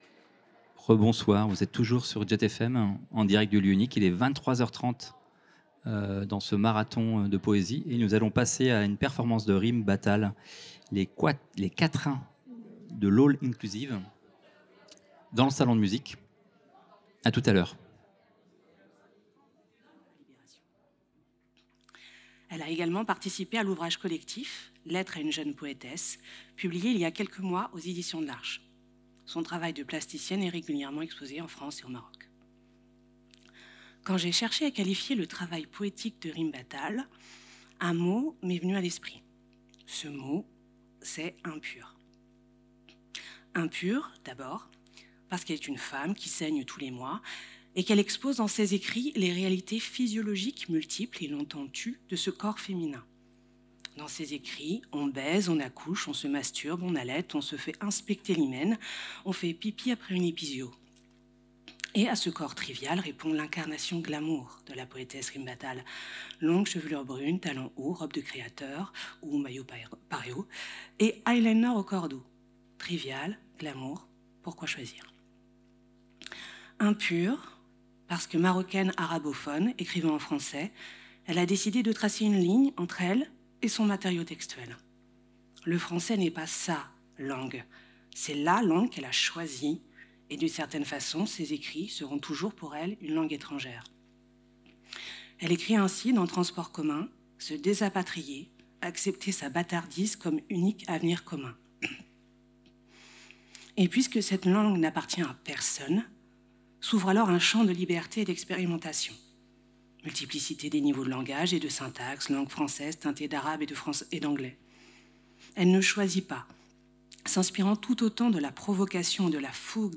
Un marathon poétique et radiophonique de plus de douze heures, en direct depuis le Lieu Unique.